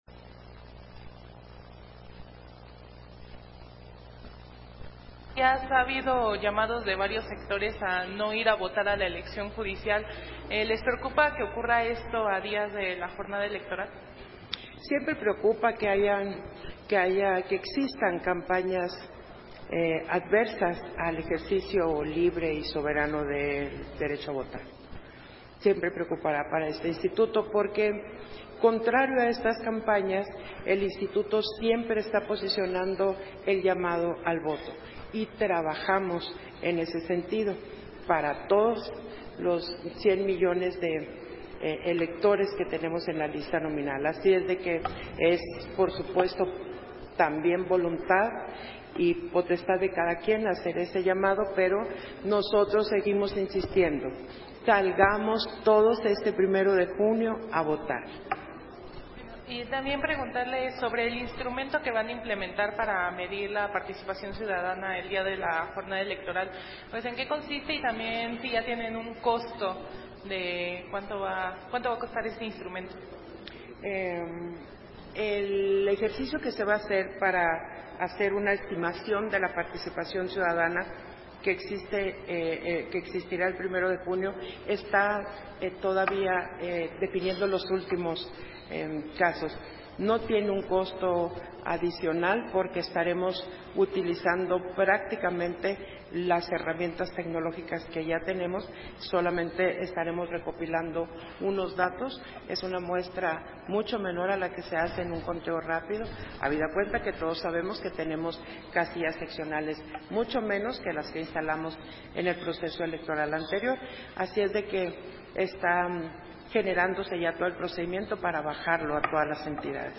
Conferencia de prensa de la Consejera Presidenta, Guadalupe Taddei, al termino de la sesión del consejo general